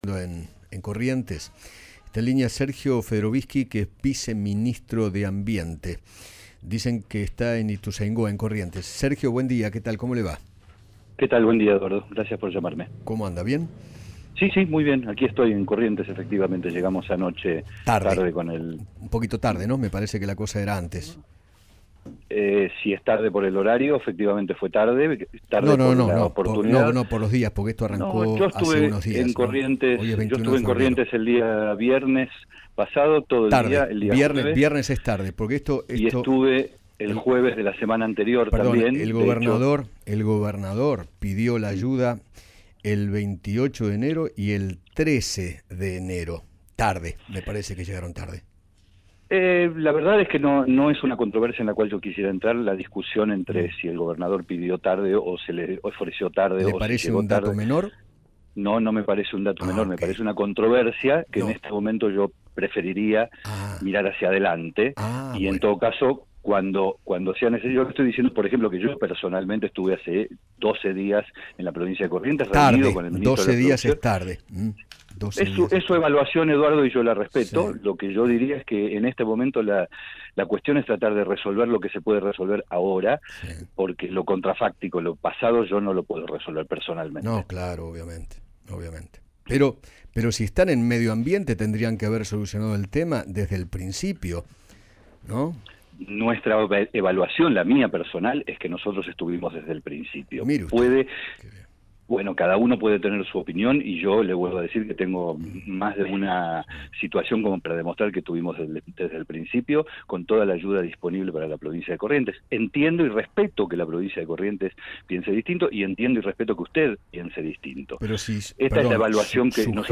Sergio Federovisky, Viceministro de Ambiente, conversó con Eduardo Feinmann sobre la demora para combatir el fuego en Corrientes y se refirió a la discusión entre el Gobernador de la provincia y el ministro Juan Cabandié.